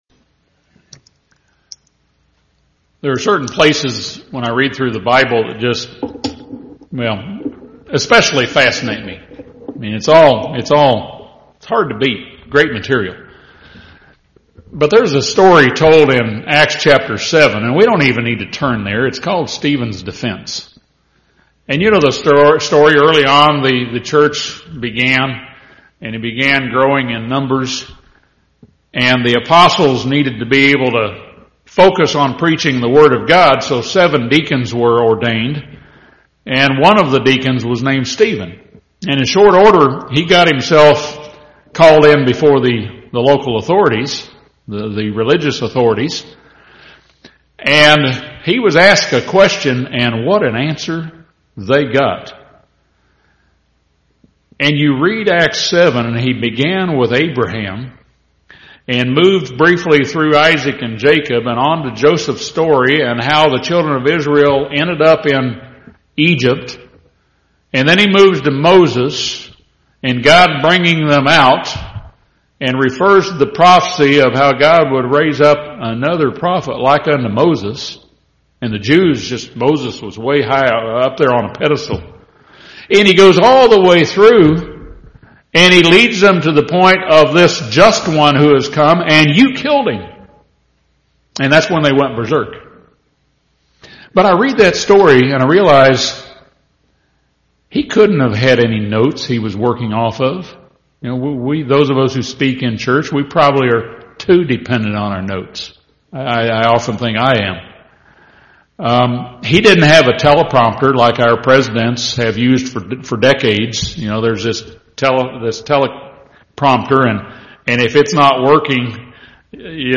We are also called to teach the Word of God. This sermon discusses several reasons why we should study the Bible and how to study the scriptures.